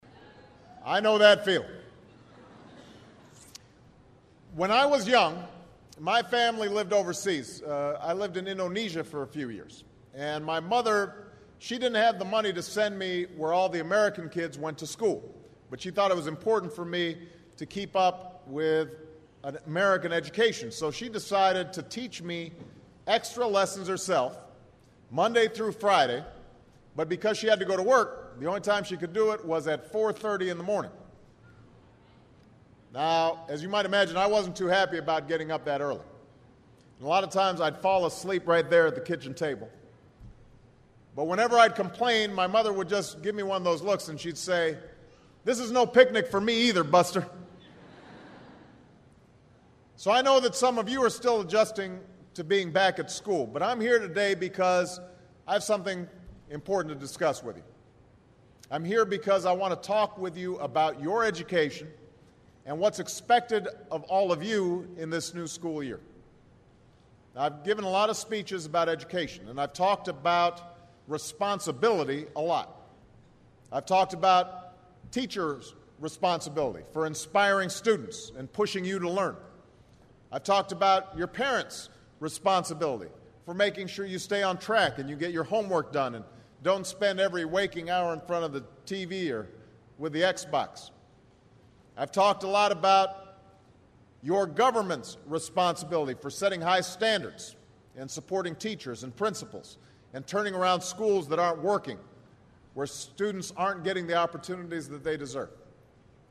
名人励志英语演讲 第2期:梦想与责任(2) 听力文件下载—在线英语听力室